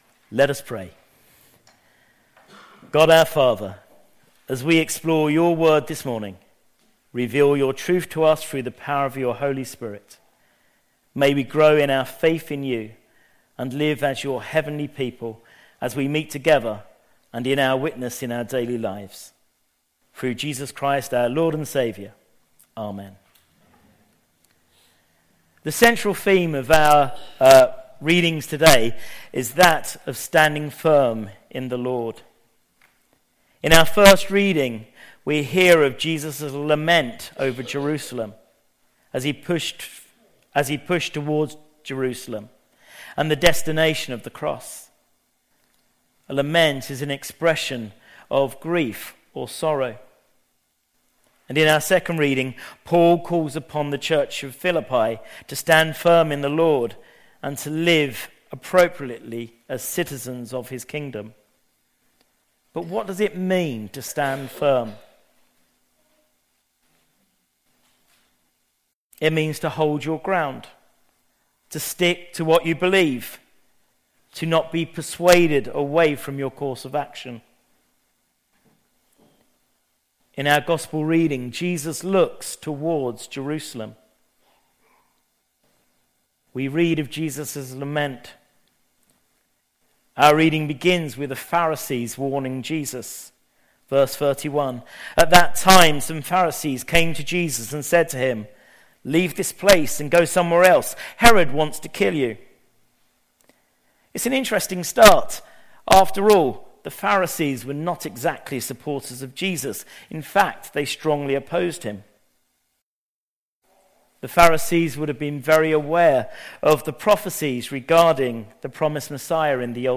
An audio version of the sermon is also available.
03-16-sermon.mp3